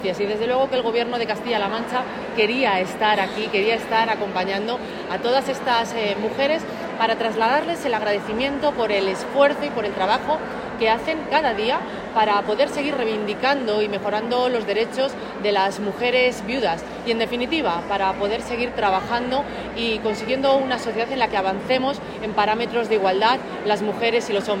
>> En la XLIX Asamblea Regional de Viudas celebrada en La Guardia (Toledo)